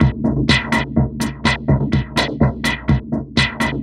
tx_perc_125_vibron2.wav